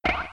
Play, download and share VV2_spring original sound button!!!!
spring.mp3